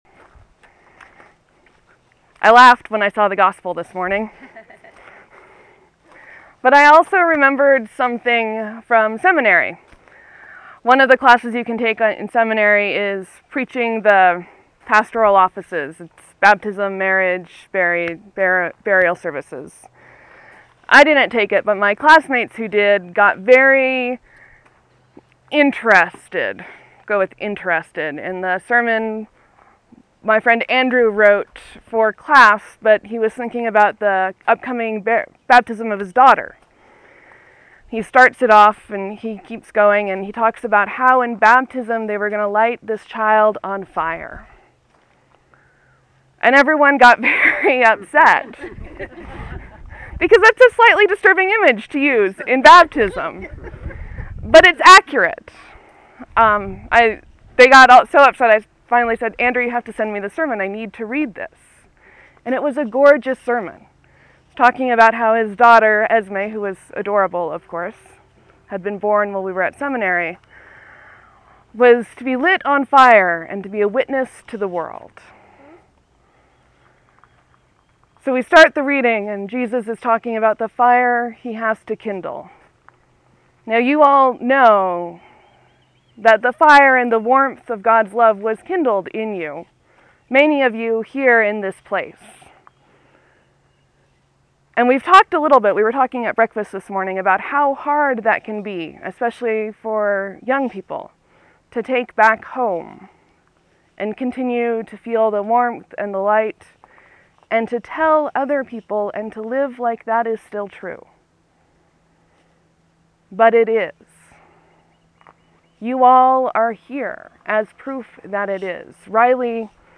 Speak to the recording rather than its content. Fire and Light, Proper 15 at Alumni Camp 8-18-13